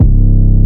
808 (goosebumps) (1).wav